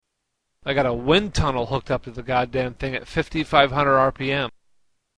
Wind tunnel